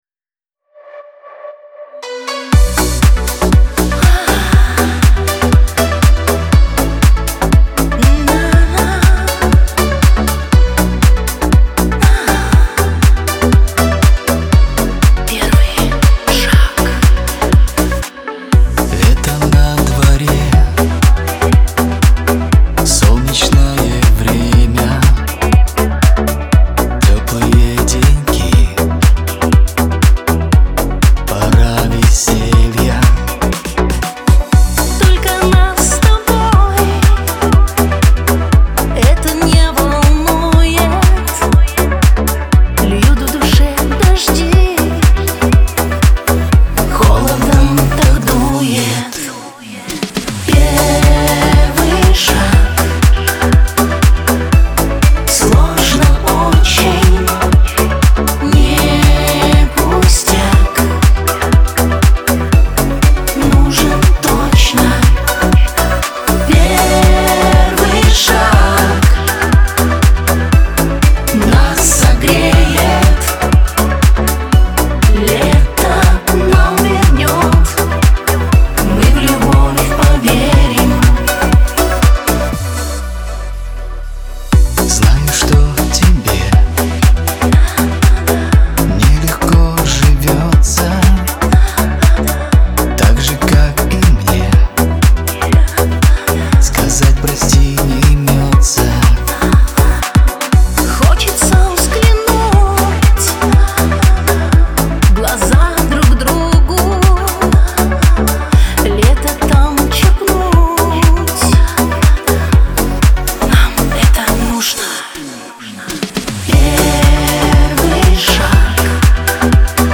диско , pop , дуэт